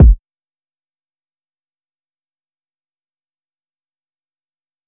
Kick (Portland).wav